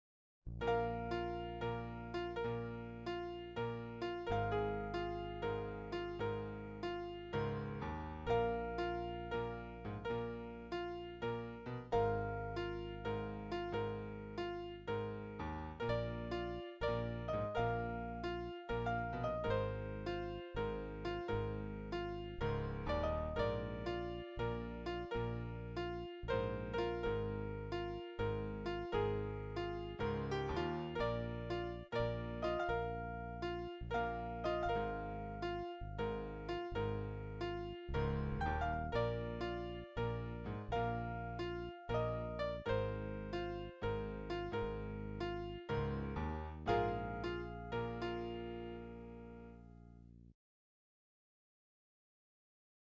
Nice little piano melody that ends abruptly